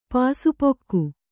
♪ pāsu pokku